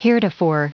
Prononciation du mot heretofore en anglais (fichier audio)
Prononciation du mot : heretofore